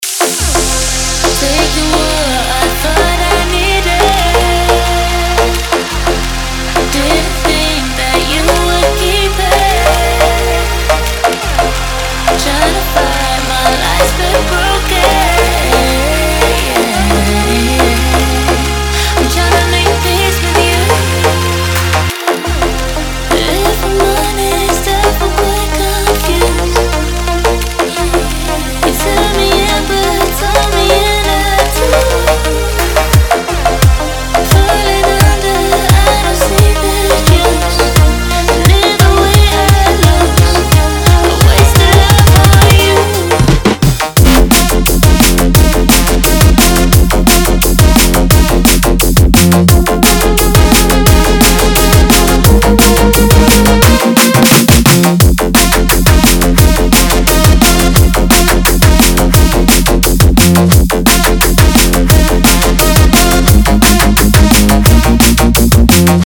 DRUM & BASS (Dancefloor)